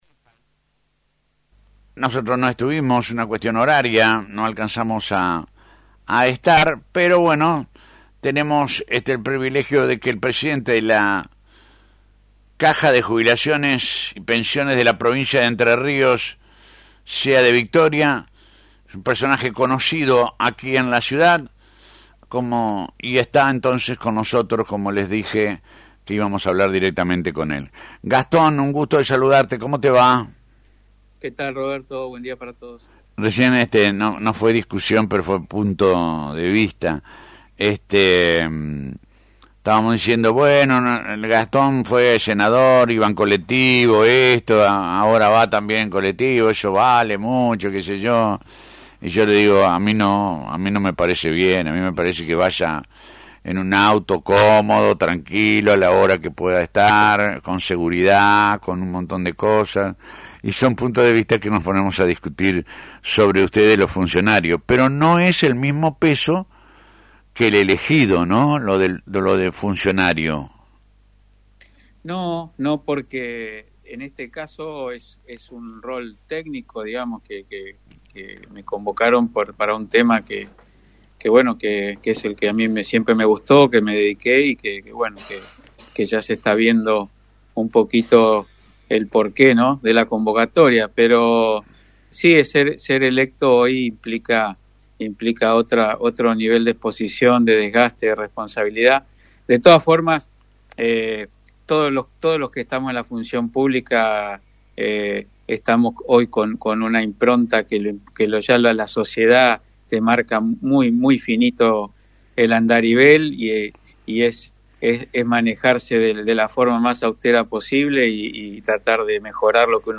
En un reportaje radial